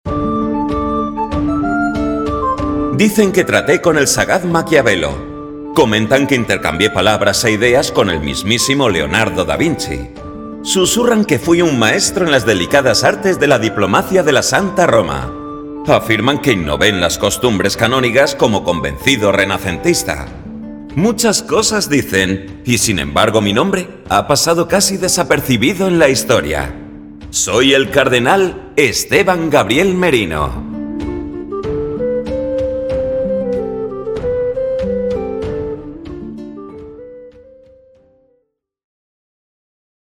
Échantillons de voix natifs
Audioguides
Neumann Tlm 103
BasseProfondHauteBas
FiableChaleureuxAutoritaireAmicalSombreDe la conversation